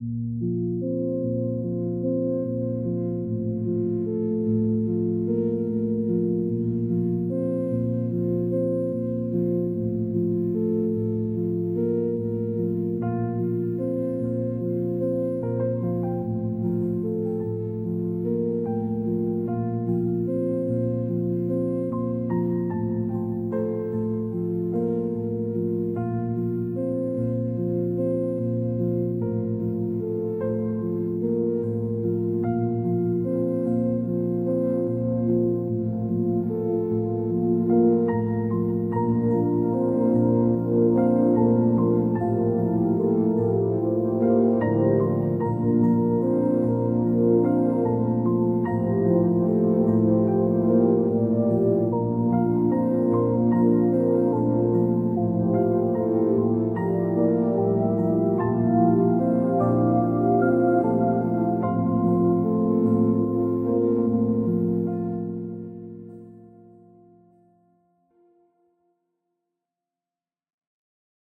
All volums remastered to same perceived volumes.